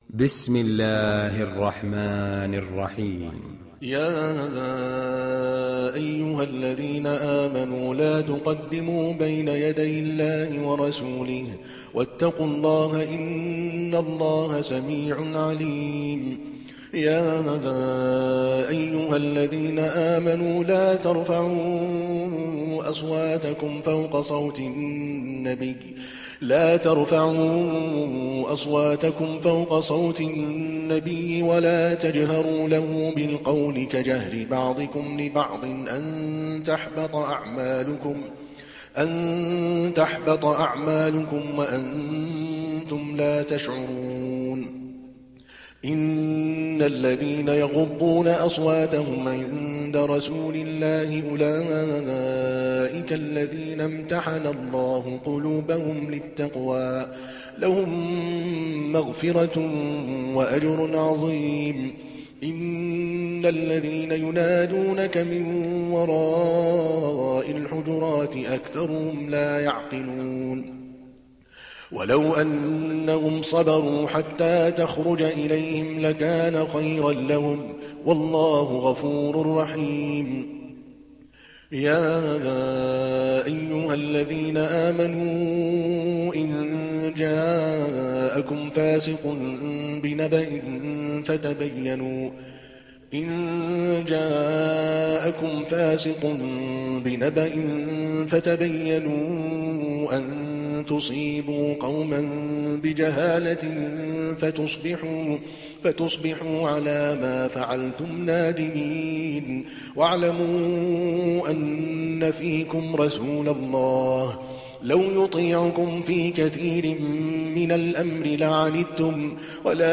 تحميل سورة الحجرات mp3 بصوت عادل الكلباني برواية حفص عن عاصم, تحميل استماع القرآن الكريم على الجوال mp3 كاملا بروابط مباشرة وسريعة